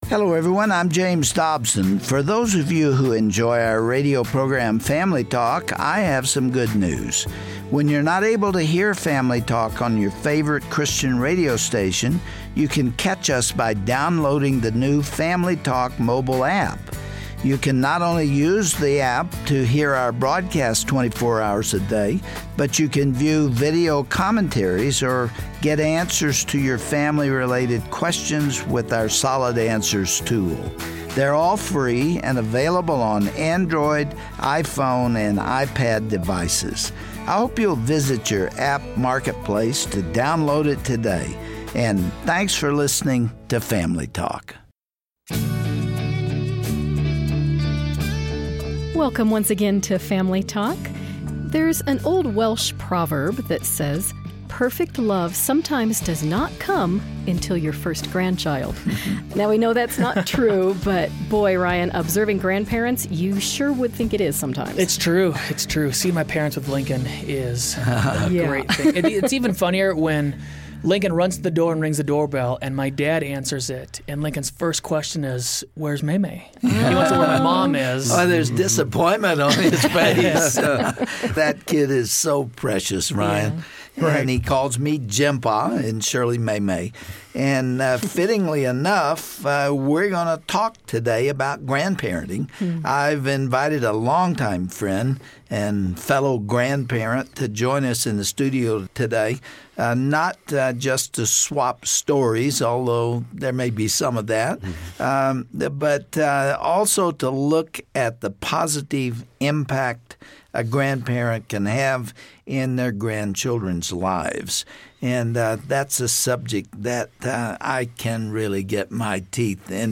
Dr. Dobson sits down with Gary Smalley to talk about the joys and God-given responsibility we have to teach spiritual truths to the next generation.